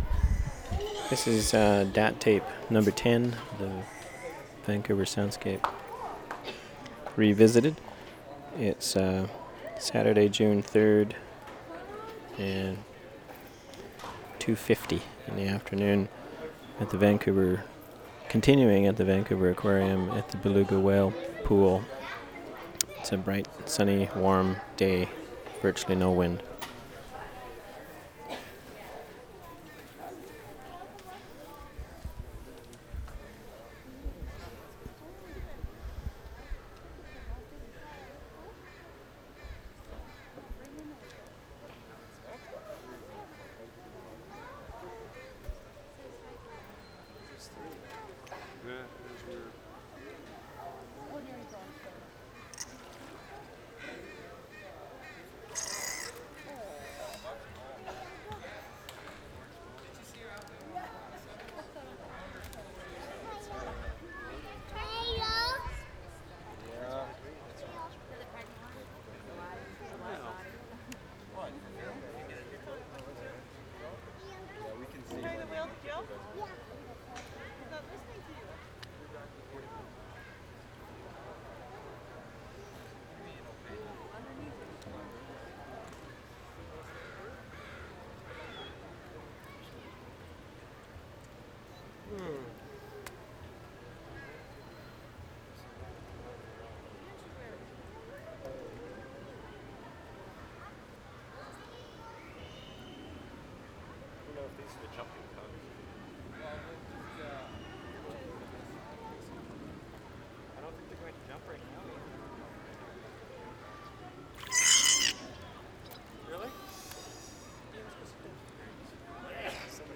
VANCOUVER AQUARIUM
Beluga Whale Pool 4:48
1. ID, conversation, very interesting whale calls at 0:58, 1:58, 2:08, 2:18, 2:29, whales continue to call and blow air getting more active at 4:30. people conversing throughout, distant jets and music